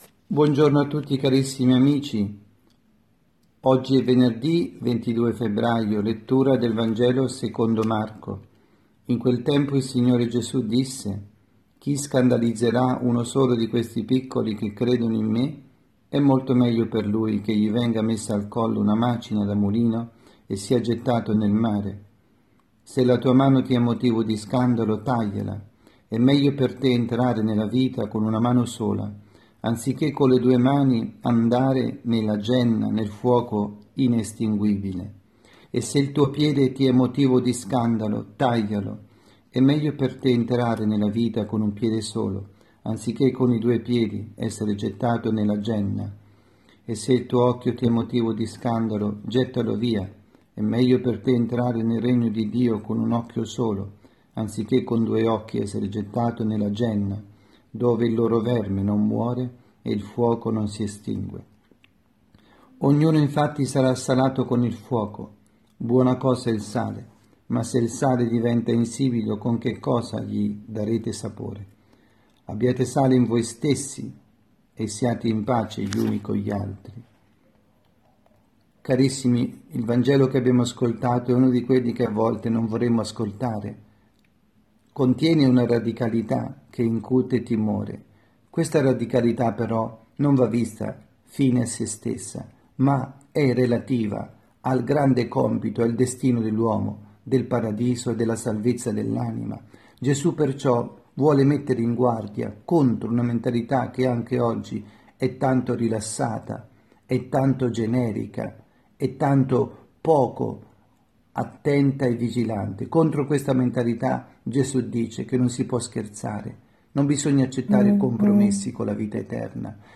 Omelia
dalla Casa di Riposo Santa Marta Milano